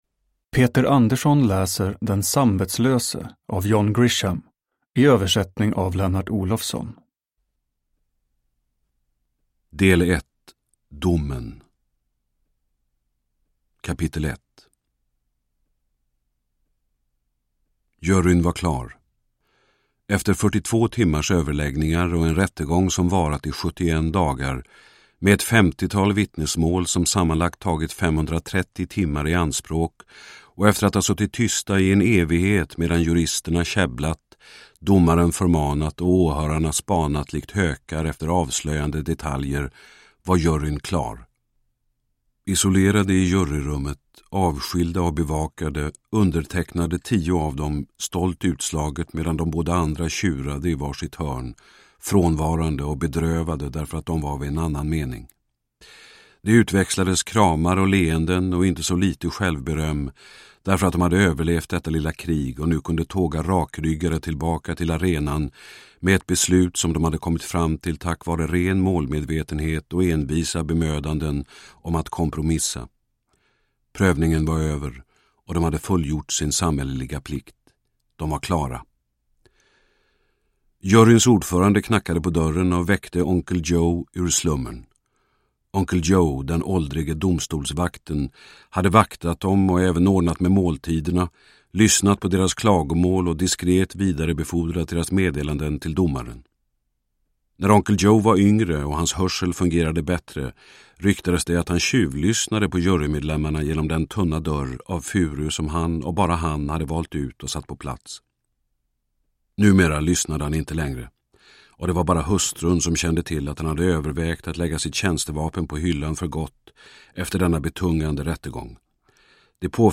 Den samvetslöse (ljudbok) av John Grisham